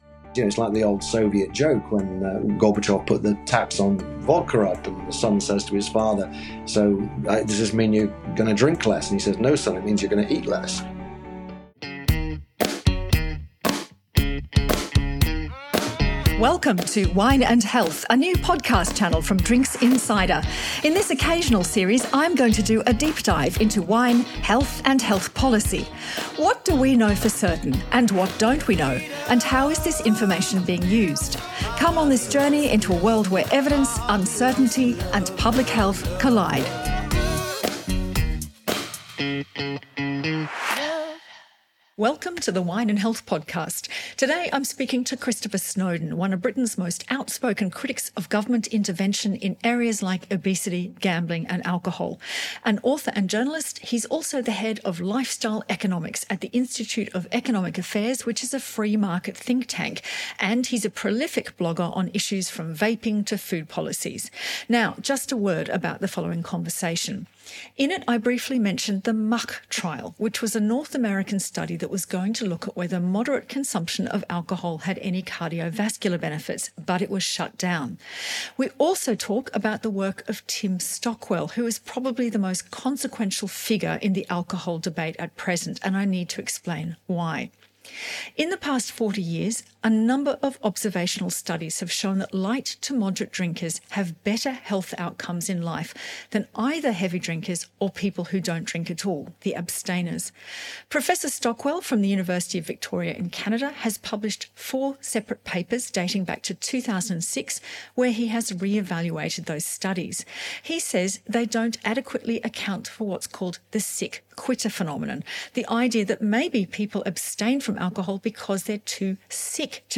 In this wide-ranging discussion, we delve into the anti-alcohol movement, exploring its implications on personal freedoms and public health.